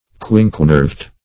Search Result for " quinquenerved" : The Collaborative International Dictionary of English v.0.48: Quinquenerved \Quin"que*nerved`\, a. [Quinque- + nerve.] (Bot.) Having five nerves; -- said of a leaf with five nearly equal nerves or ribs rising from the end of the petiole.